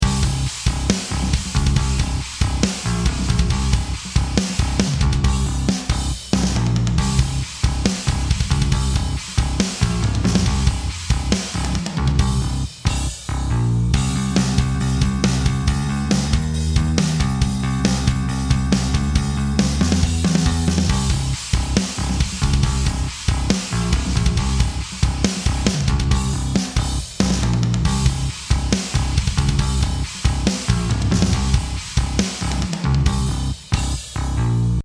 Not only will this bring the individual drum hits closer together, but it will also bring out the room or the ambience of the original recording.
This will keep the very low frequencies (i. e. the kick drum) out of the compression circuit, thereby preventing unpleasant pumping.
…and this is how the drums sound with C165a engaged:
C165a-Drum-Bus-WET.mp3